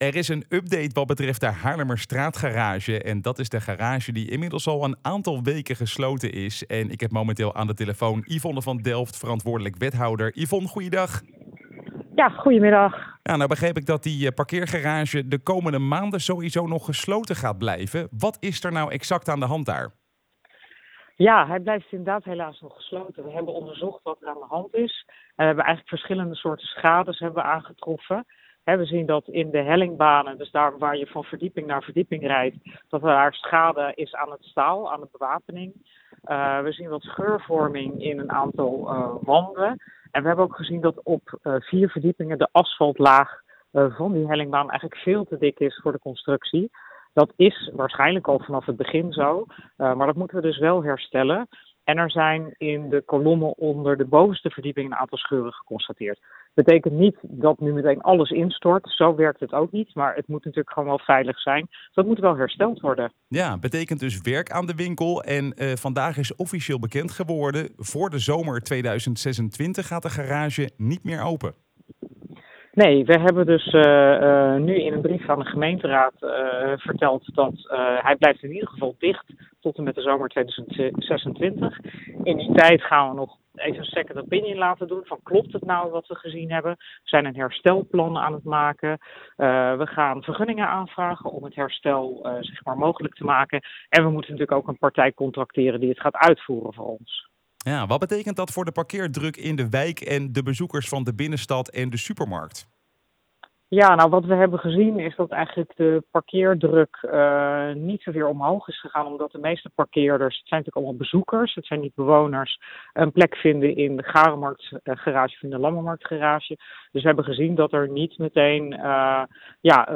Wethouder Yvonne van Delft over de staat van de Haarlemmerstraatgarage:
Yvonne-van-Delft-over-de-Haarlemmerstraat-garage-Leiden.wav